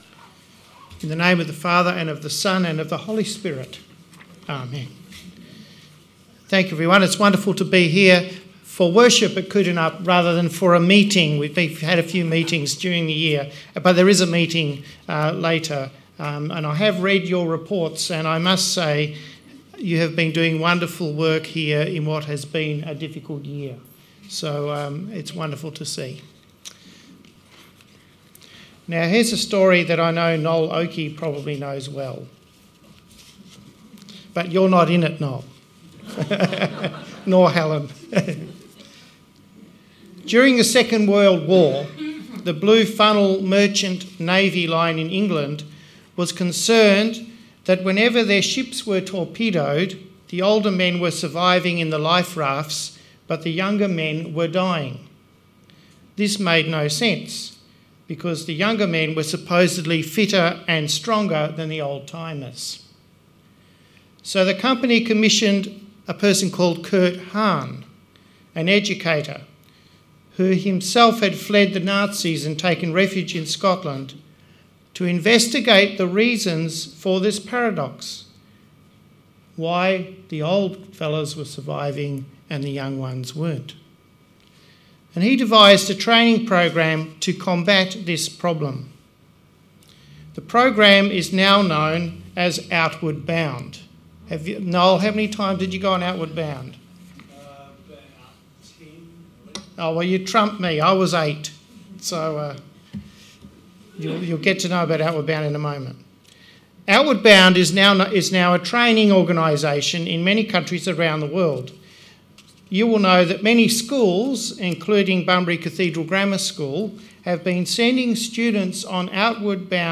Sermon 18th February